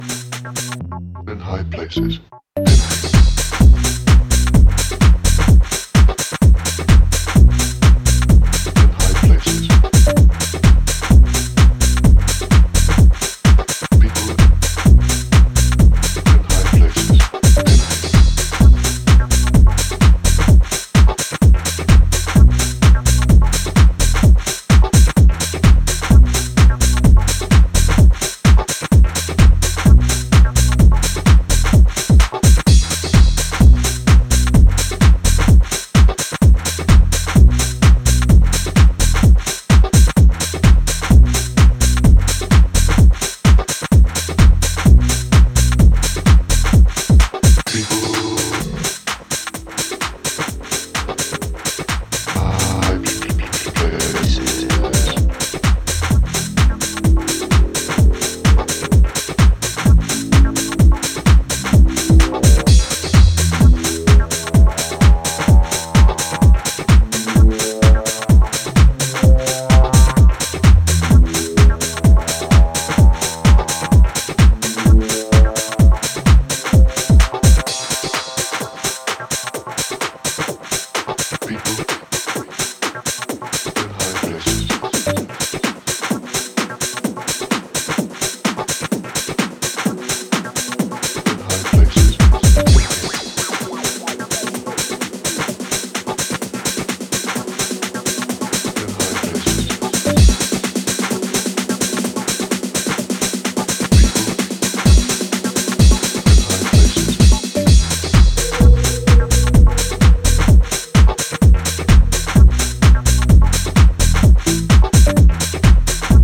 bleepy and bold house music